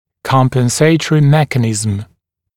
[ˌkɔmpən’seɪtərɪ ‘mekənɪzəm][ˌкомпэн’сэйтэри ‘мэкэнизэм]компенсаторный механизм